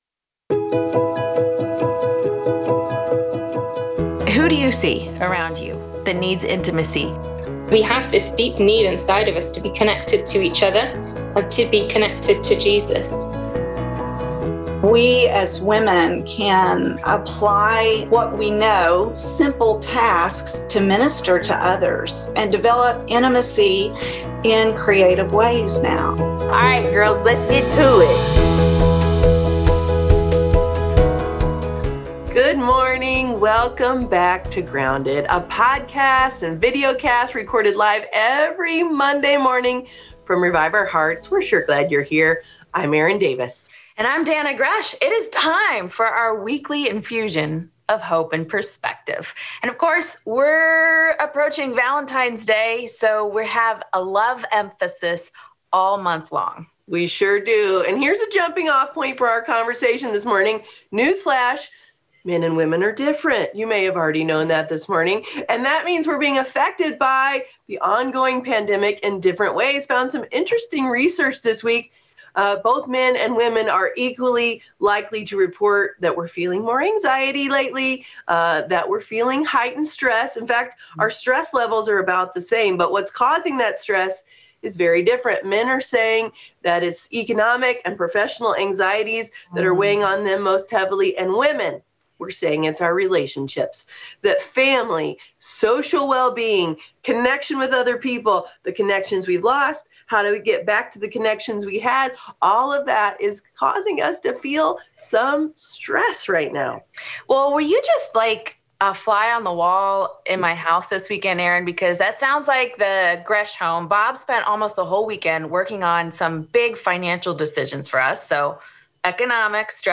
You’ll also hear from a panel of wise women who will talk about singleness and answer some of your